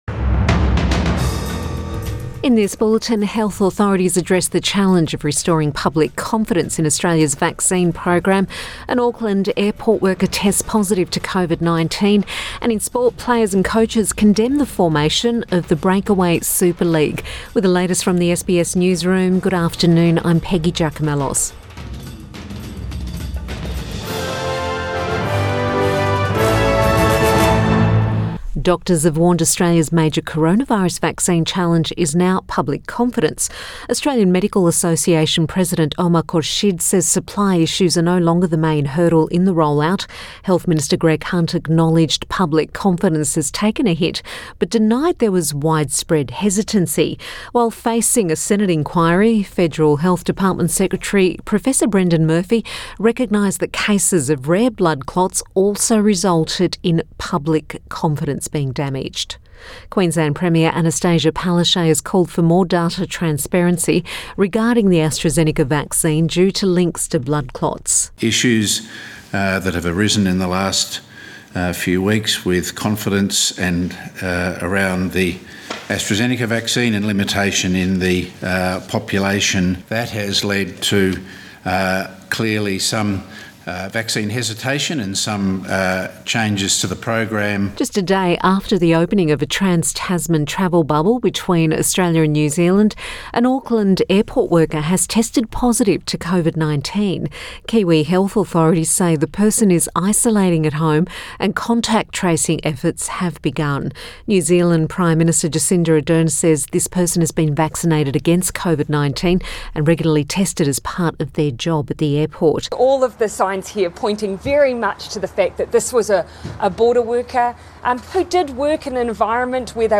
PM bulletin 20 April 2021